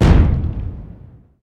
bomber sounds
bombDet1.ogg